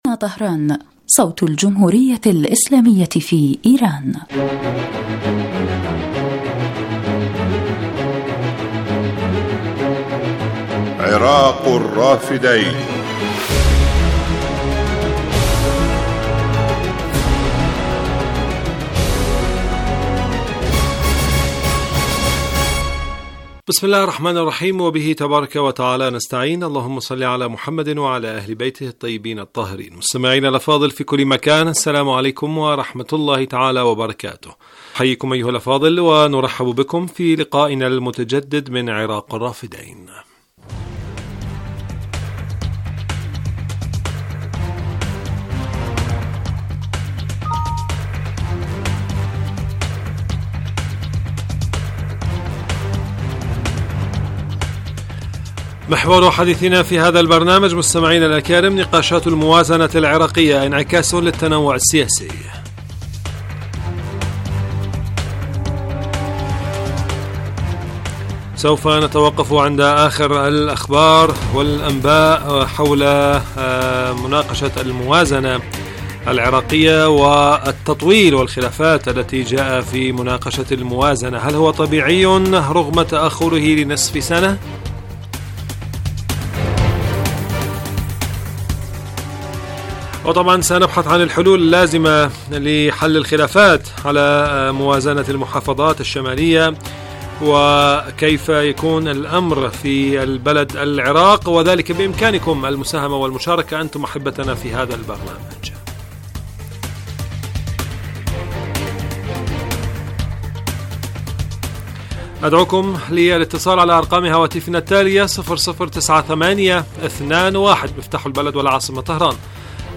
برنامج حي يتناول بالدراسة والتحليل آخرالتطورات والمستجدات على الساحة العراقية وتداعيات على الإقليم من خلال استضافة خبراء سياسيين ومداخلات للمستمعين عبر الهاتف.
يبث هذا البرنامج على الهواء مباشرة أيام السبت وعلى مدى نصف ساعة